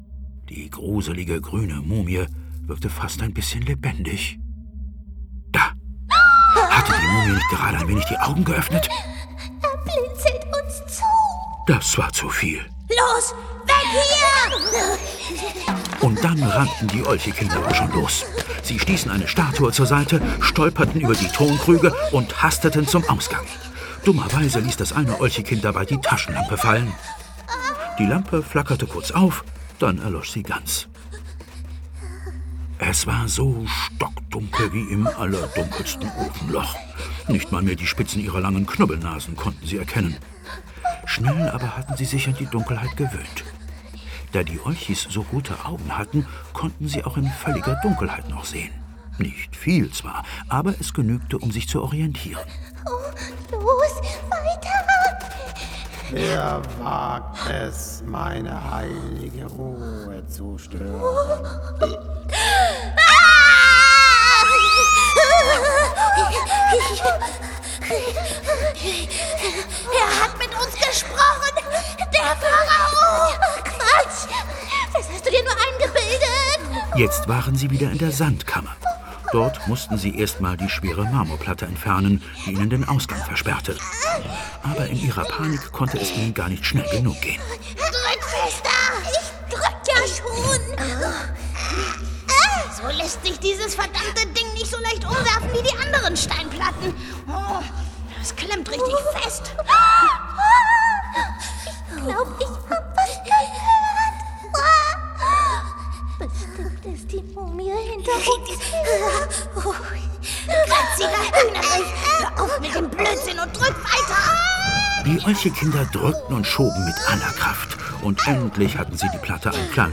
Ravensburger Die Olchis und die grüne Mumie ✔ tiptoi® Hörbuch ab 5 Jahren ✔ Jetzt online herunterladen!
Die_Olchis_und_die_gruene_Mumie-Hoerprobe.mp3